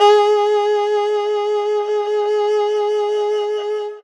52-bi06-erhu-f-g#3.aif